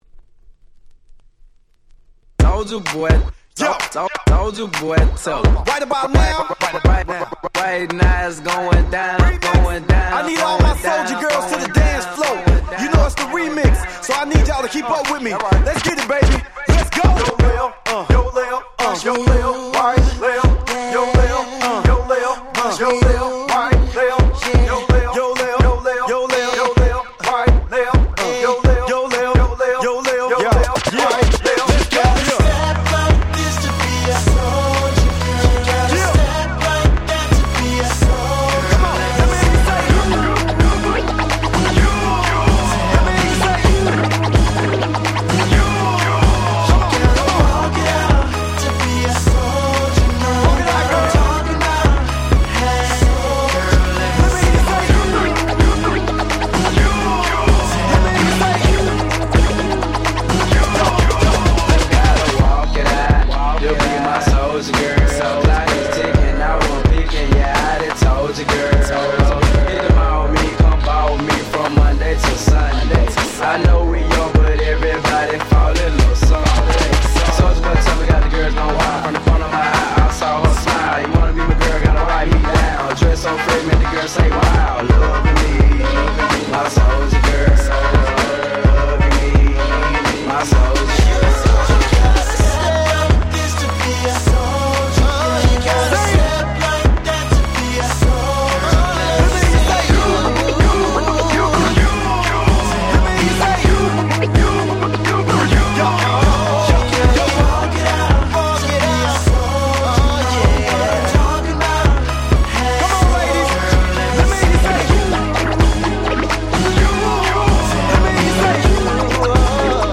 07' Super Hit Southern Hip Hop !!